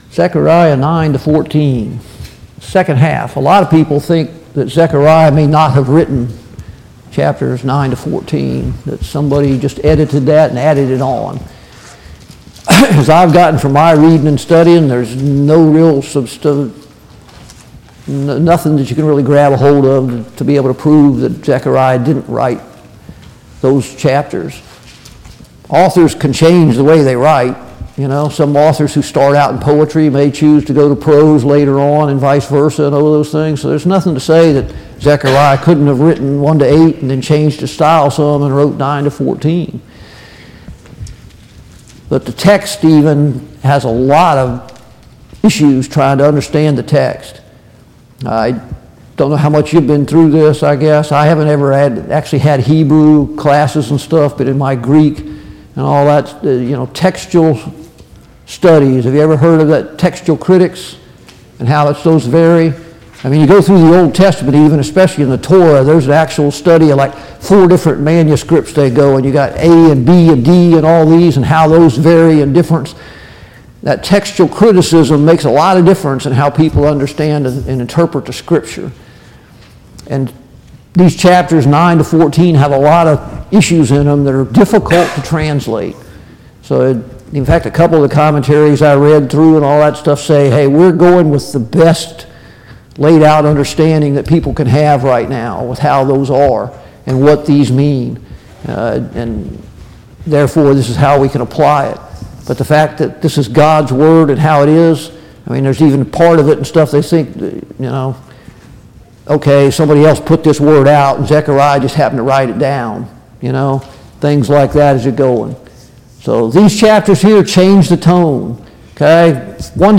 Study on the Minor Prophets Passage: Zechariah 9-14 Service Type: Sunday Morning Bible Class « 25.